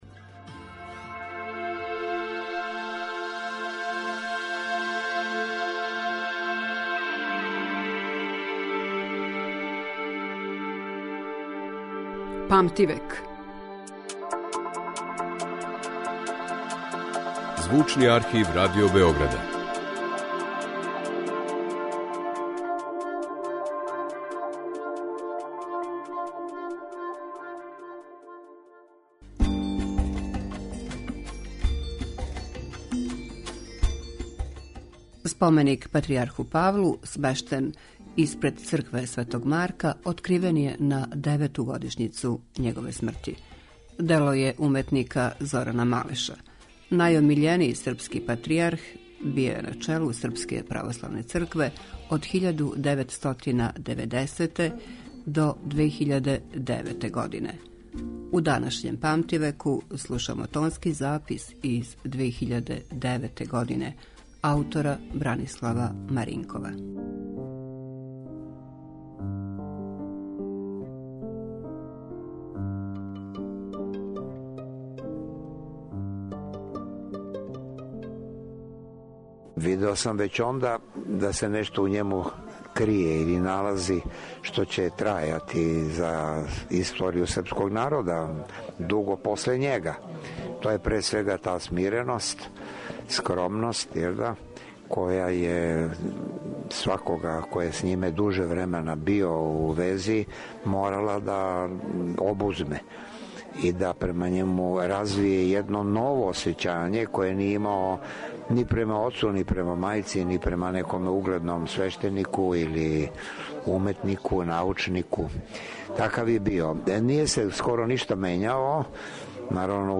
Емисија која ће покушати да афирмише богатство Звучног архива Радио Београда, у коме се чувају занимљиви, ексклузивни снимци стварани током целог једног века, колико траје историја нашег радија.